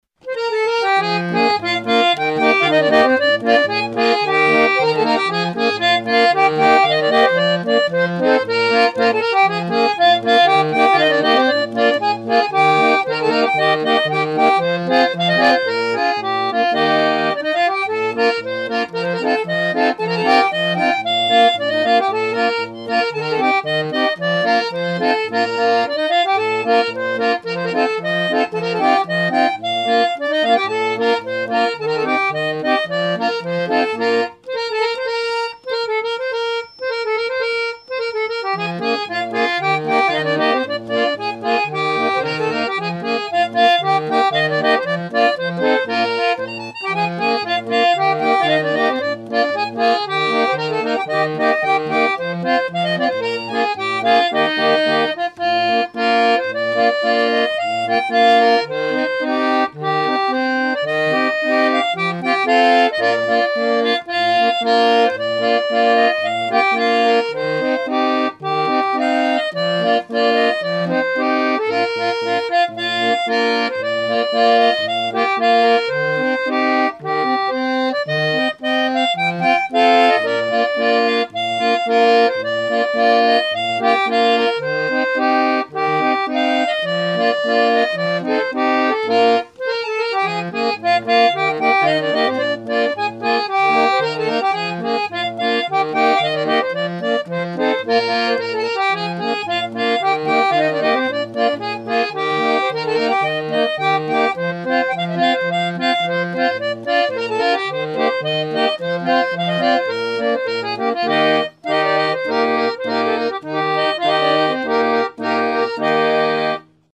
danse : polka
Emissions de la radio RCF Vendée
musique mécanique